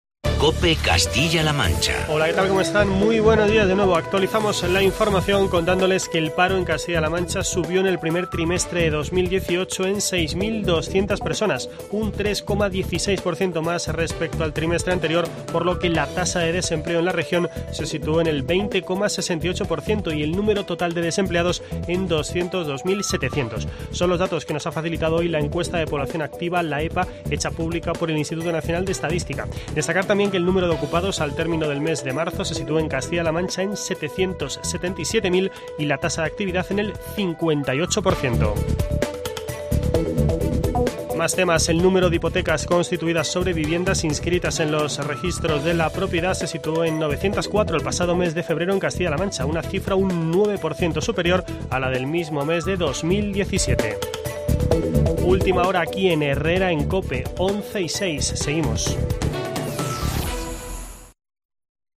Franco, durante una rueda de prensa este jueves en Toledo, ha señalado también que, en datos interanuales que, según ella, muestran "una foto más real" de la situación, "baja el paro, aumenta la ocupación y baja la tasa de paro".
Boletín informativo de COPE Castilla-La Mancha.